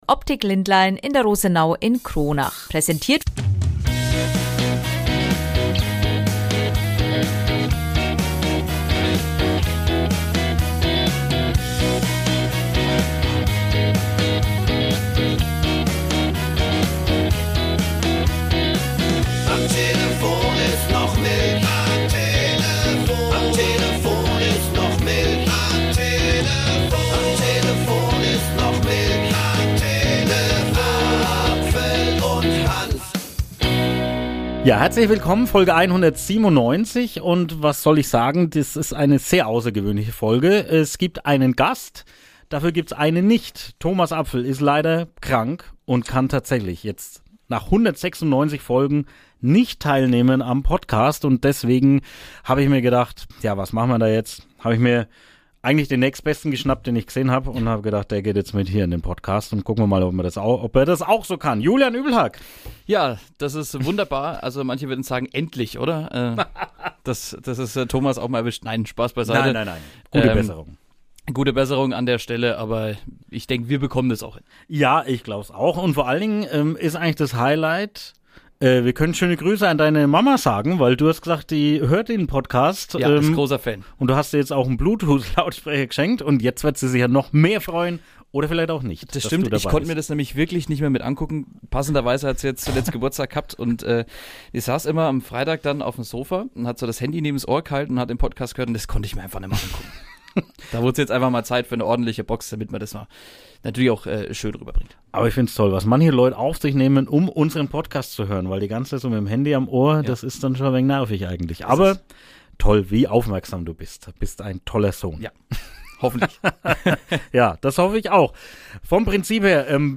Dabei blicken sie natürlich auf alles um sich rum, also in die Landkreis Coburg, Kronach und Lichtenfels. Dazu gibt es viele Berichte und Interviews